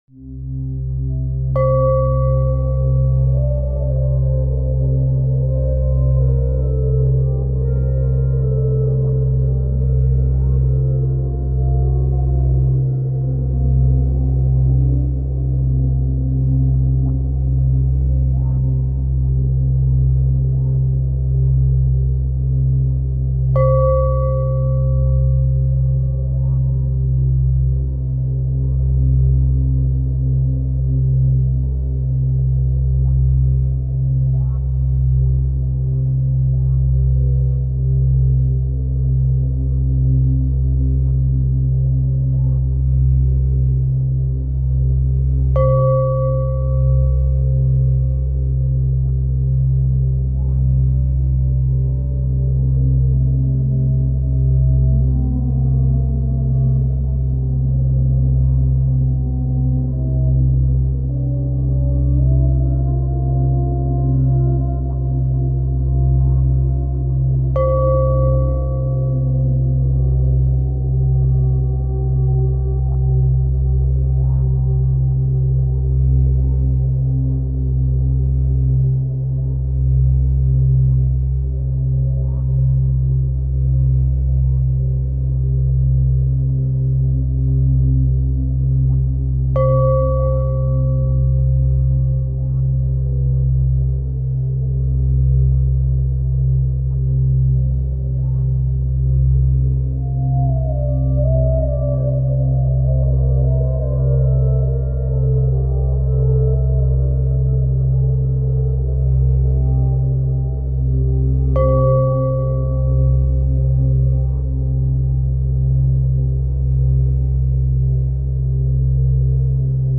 528hz.mp3